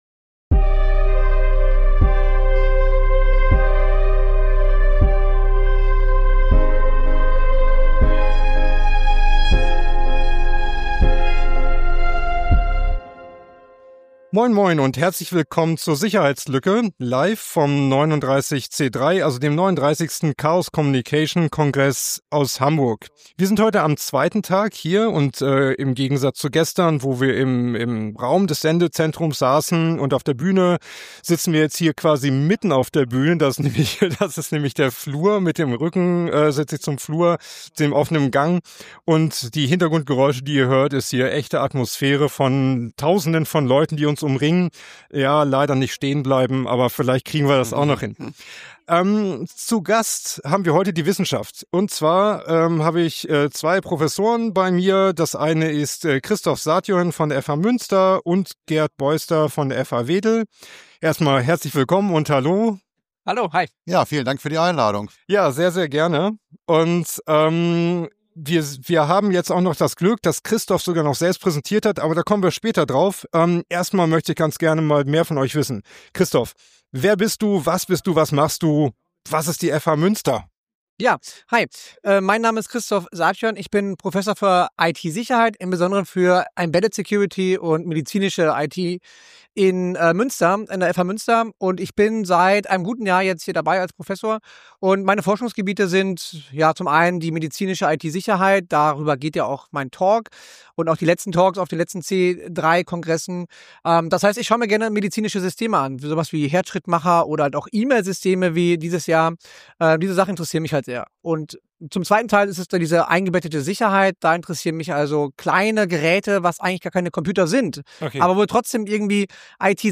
Wir senden in diesem Jahr jeden Tag live vom 39C3, dem 39. Chaos Communication Congress in Hamburg. In dieser Folge sprechen wir auf der Bühne des Sendezentrums über die Vorträge von Tag 2, die wir empfehlen möchten, und darüber, weshalb wir sie interessant und relevant finden.